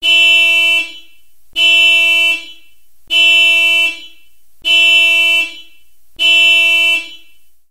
Alarma-bocinazos
Alarma-bocinazos.mp3